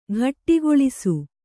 ♪ ghattigoḷisu